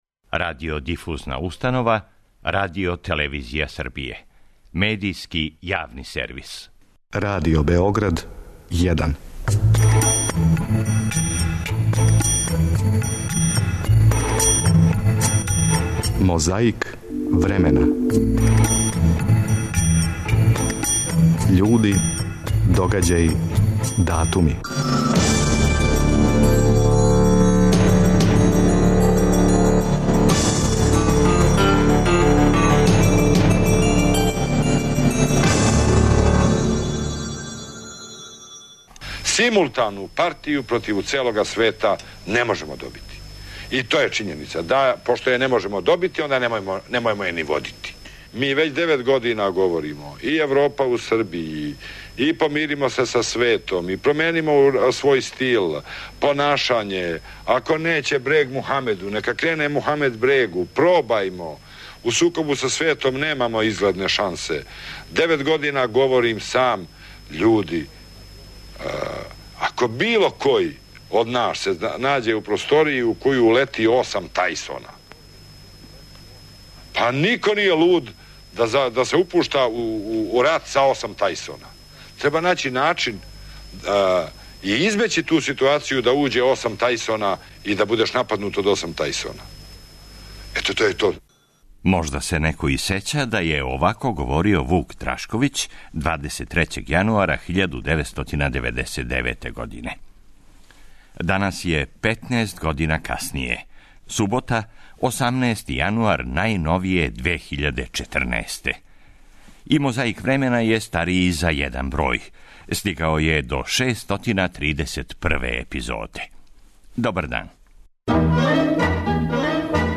Да ли се неко сећа како је говорио Вук Драшковић 23. јануара 1999. године?
Подсећа на прошлост (културну, историјску, политичку, спортску и сваку другу) уз помоћ материјала из Тонског архива, Документације и библиотеке Радио Београда.